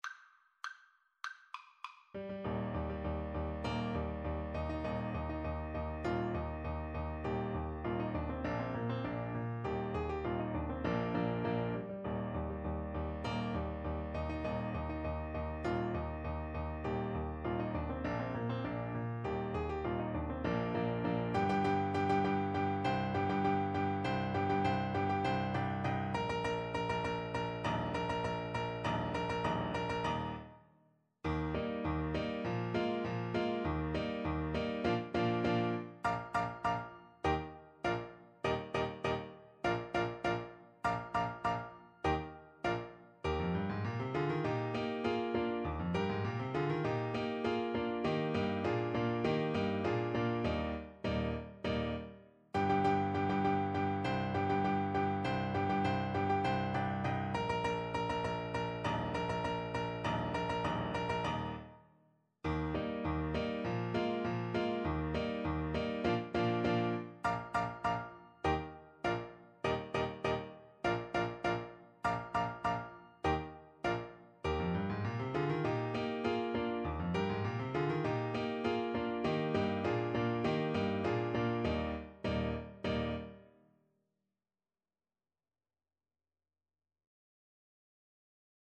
Play (or use space bar on your keyboard) Pause Music Playalong - Piano Accompaniment Playalong Band Accompaniment not yet available transpose reset tempo print settings full screen
Eb major (Sounding Pitch) C major (Alto Saxophone in Eb) (View more Eb major Music for Saxophone )
2/2 (View more 2/2 Music)
March = c.100
Classical (View more Classical Saxophone Music)